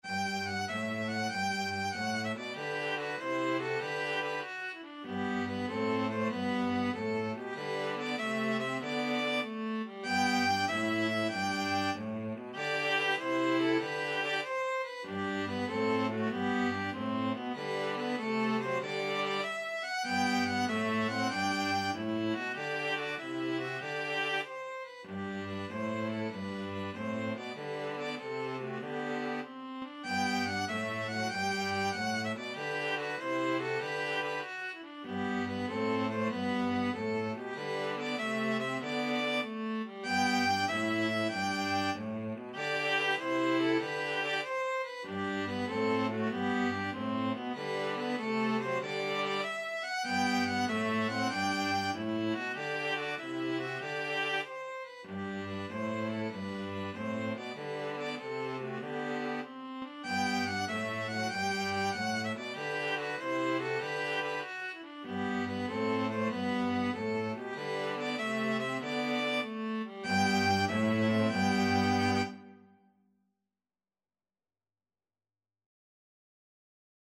Classical Trad. Sumer is icumen in (Summer is a-coming in) String trio version
ViolinViolaCello
3/8 (View more 3/8 Music)
G major (Sounding Pitch) (View more G major Music for String trio )
Happily .=c.96
Classical (View more Classical String trio Music)